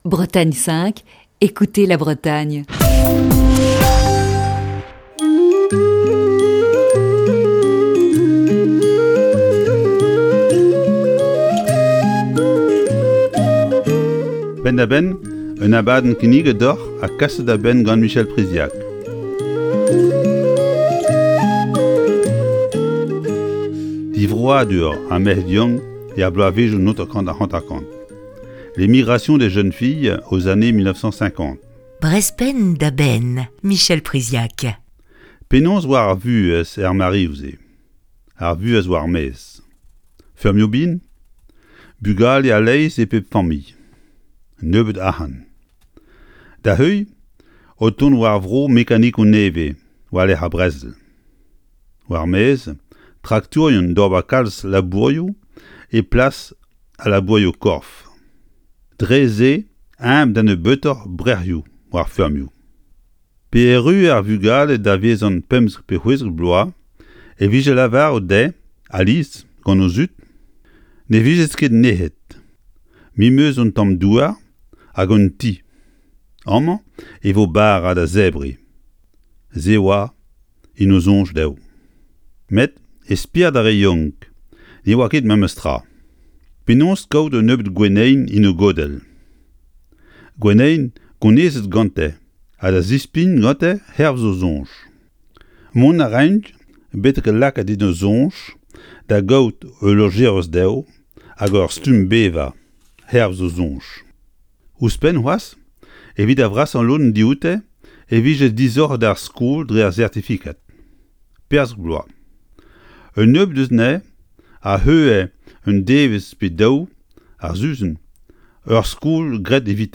Chronique du 12 avril 2021. Dans les années 1950, nombre de jeunes filles souhaitaient quitter les campagnes, aux conditions de vie parfois difficiles, pour construire un avenir meilleur en ville.